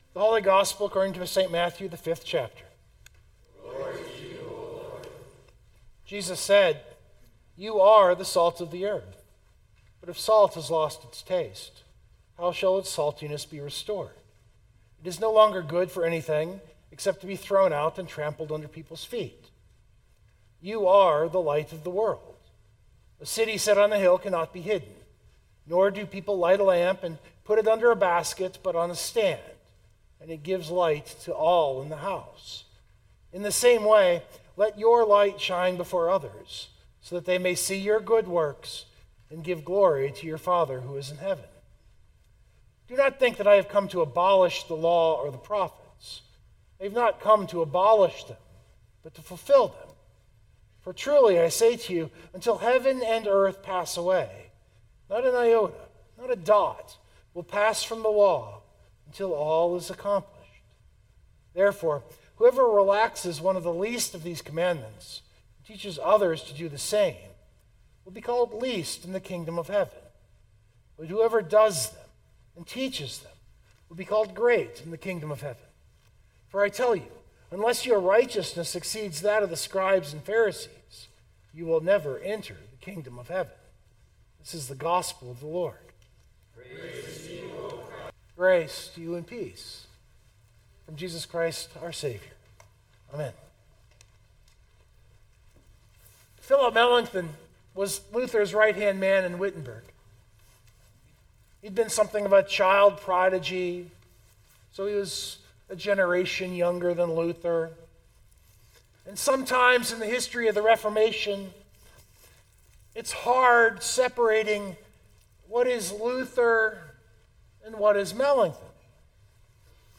This sermon preaches salt and light as Luther would have it. The salt being the stinging but preserving function of the law while the light is the proclamation of the gospel of free grace.